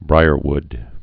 (brīər-wd)